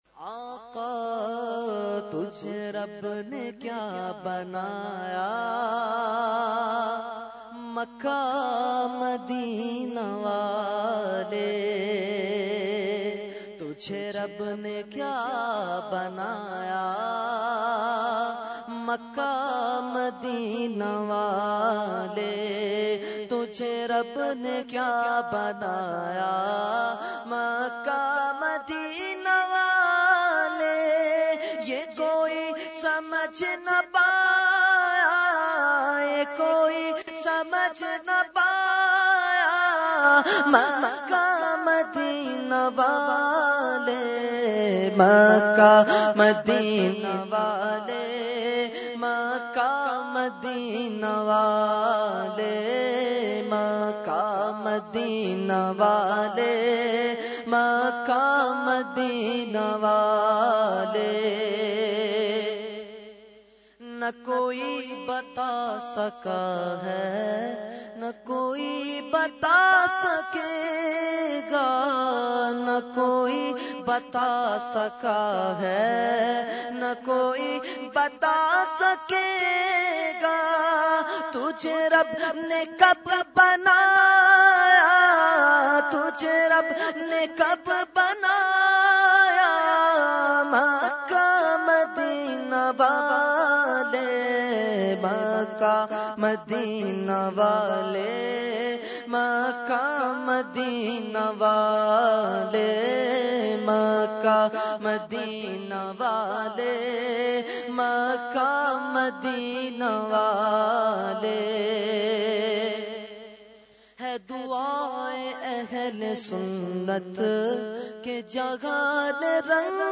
Category : Naat | Language : UrduEvent : Shab e Baraat 2014